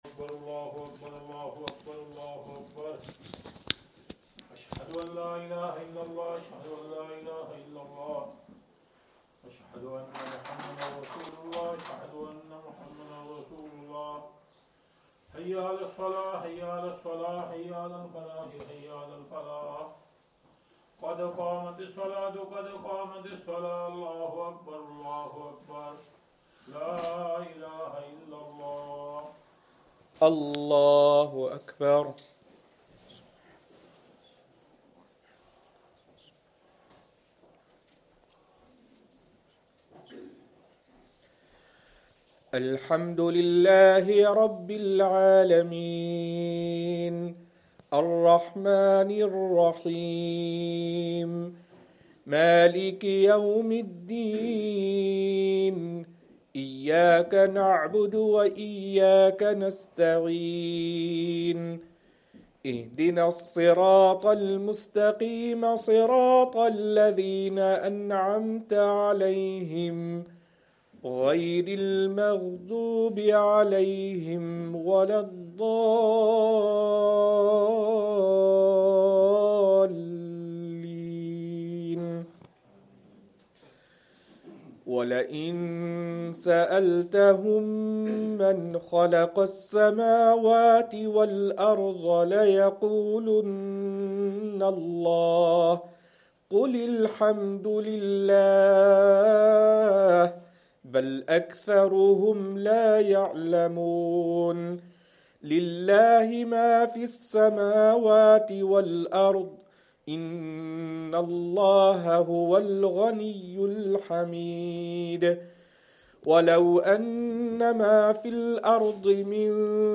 Isha
Madni Masjid, Langside Road, Glasgow